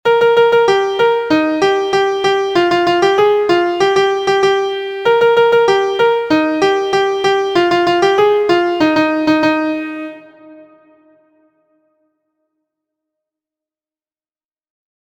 Introducing sixteenth notes with syncopation.
• Origin: Jamaican Game Song
• Key: E flat Major
• Time: 2/4
• Form: ABAB